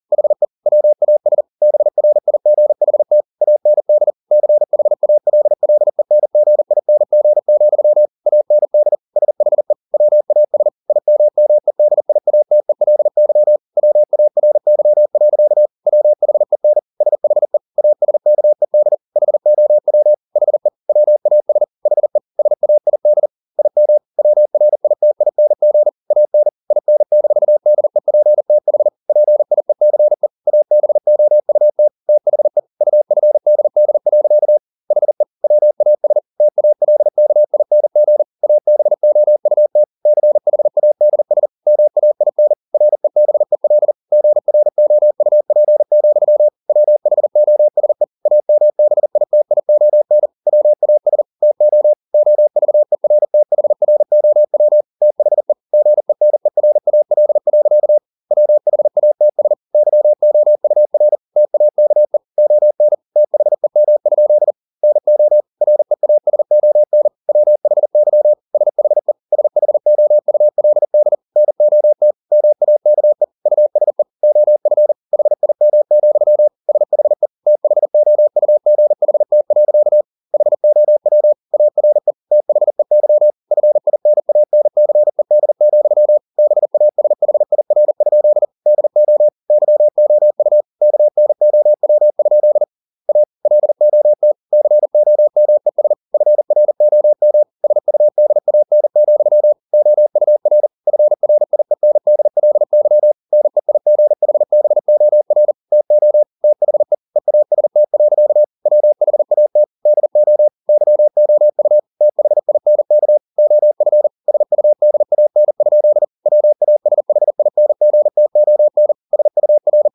Never 40wpm | CW med Gnister
Never_0040wpm.mp3